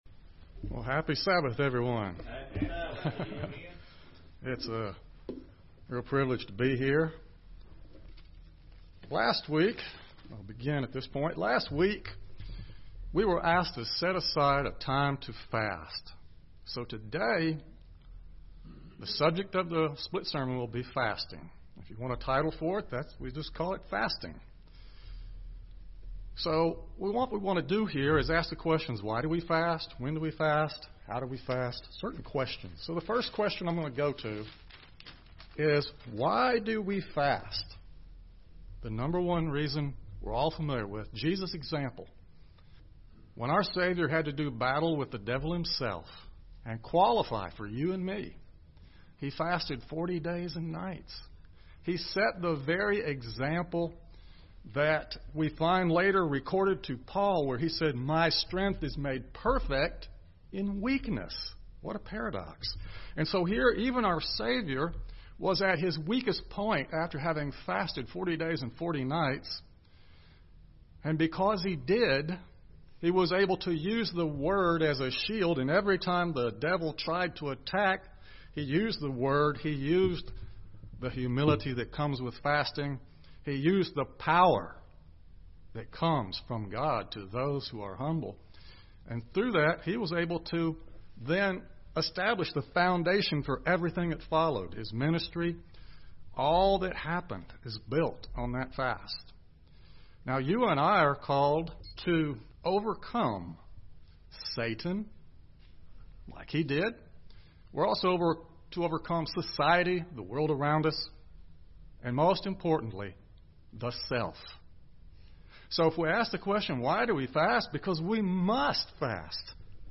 UCG Sermon Studying the bible?
Given in Gadsden, AL